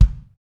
KIK XR.BD03L.wav